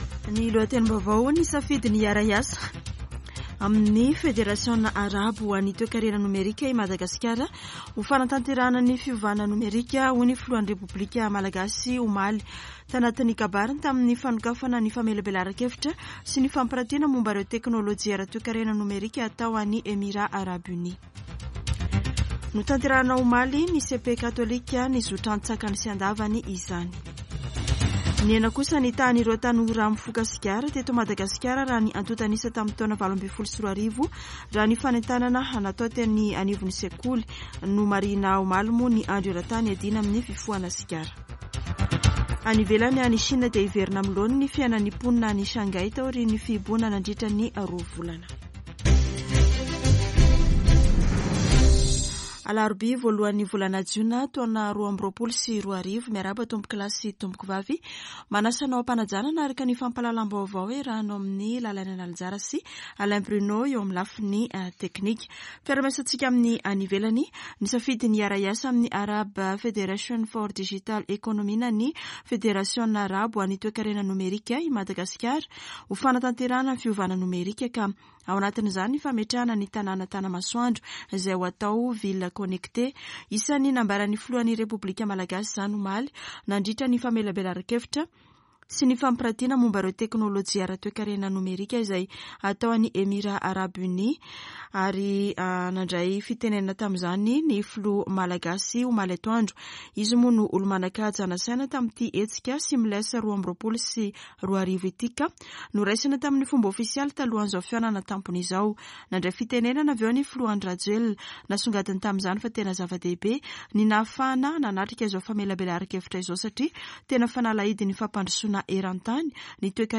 [Vaovao maraina] Alarobia 01 jona 2022